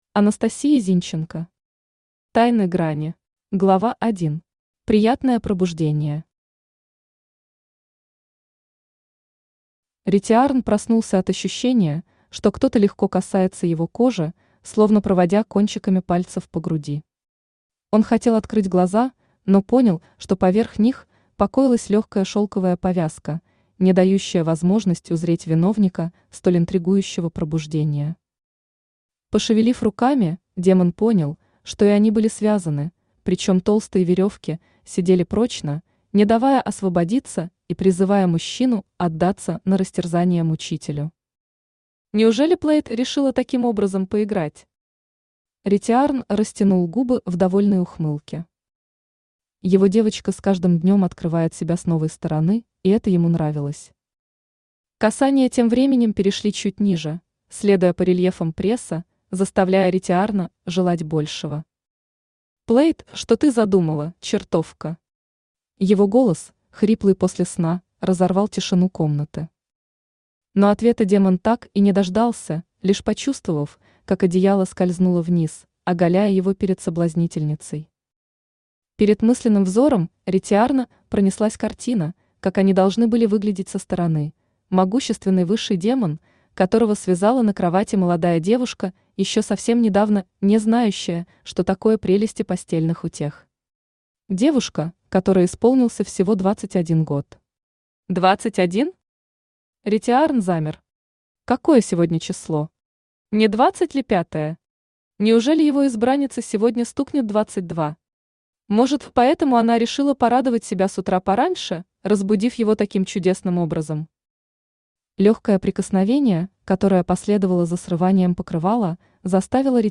Аудиокнига Тайны Грани | Библиотека аудиокниг
Aудиокнига Тайны Грани Автор Анастасия Зинченко Читает аудиокнигу Авточтец ЛитРес.